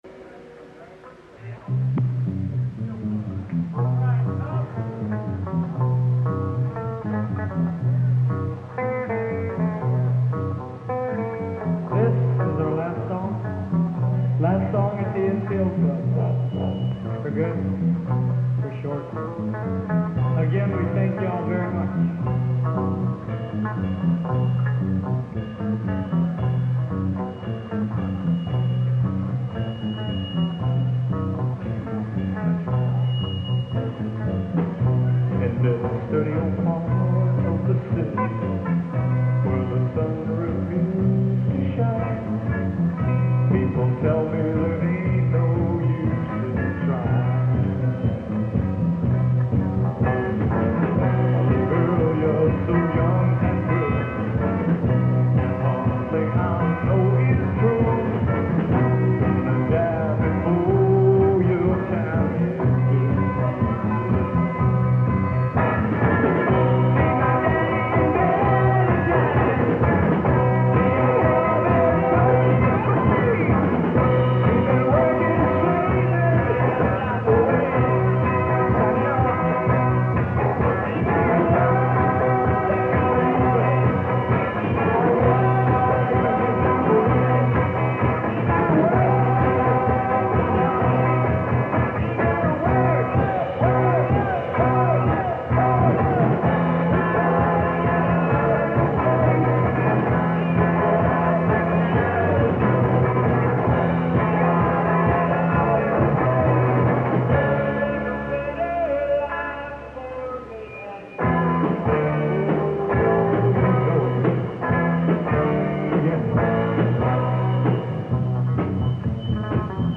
Download live performance recorded at PAS NCO Club (3.29MB)
Bass
Lead Singer
Keyboards
Drums
Guitar